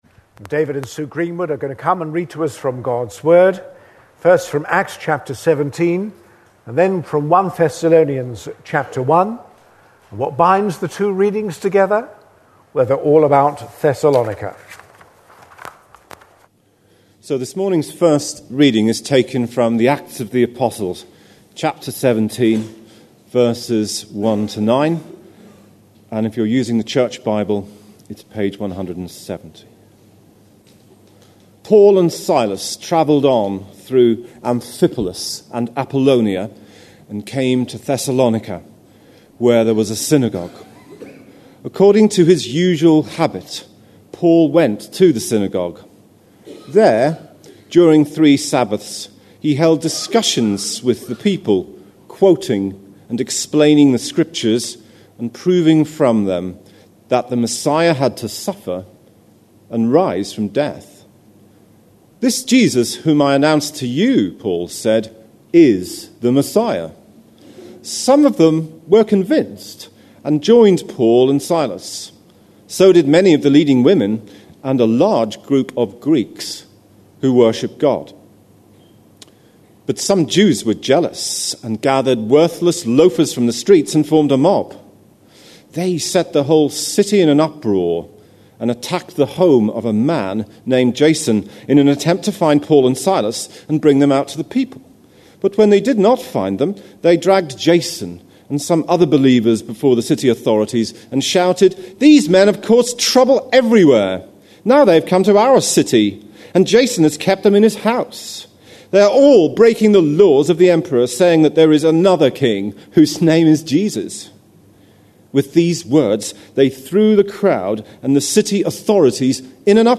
A sermon preached on 4th July, 2010, as part of our Acts series.
(Slight interference on sound.)